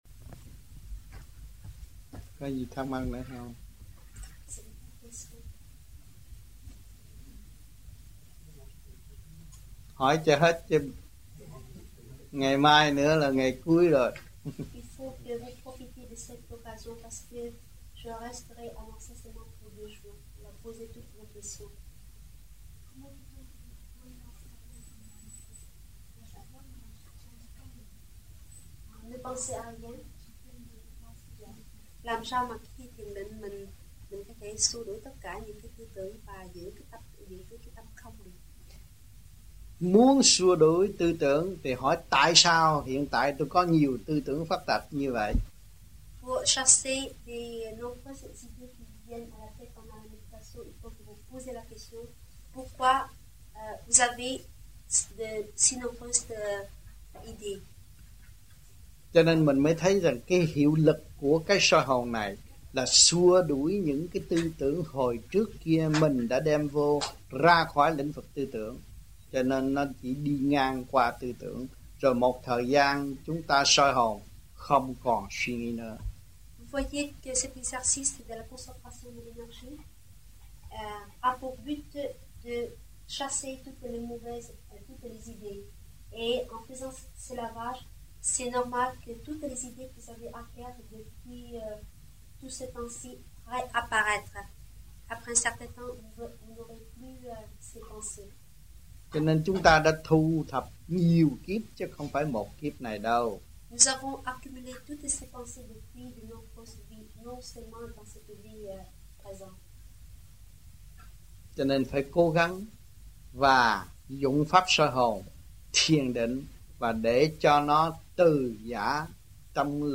1982-12-11 - MARSEILLE - THUYẾT PHÁP 02